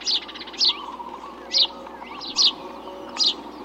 House Sparrow
House_Sparrow.mp3